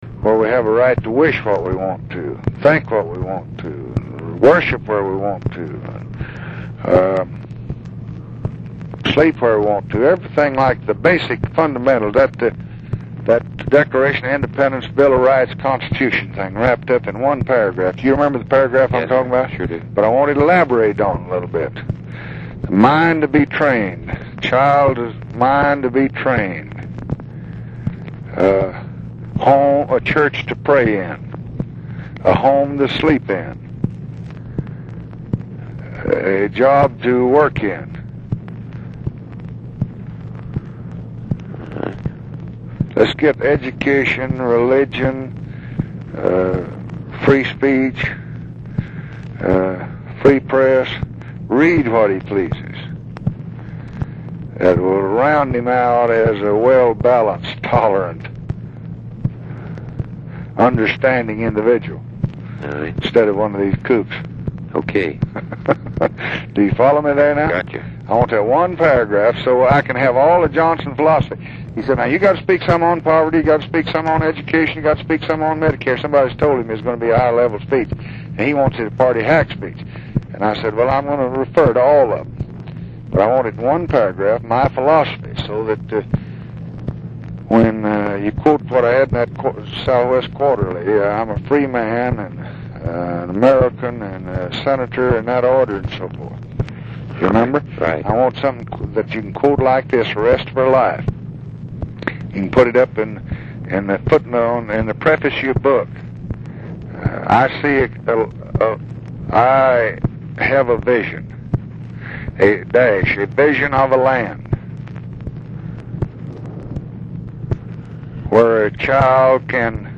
The clip below, with aide Bill Moyers, illustrates the President’s difficulty in spelling out a positive economic program that would appeal to “frontlash” voters.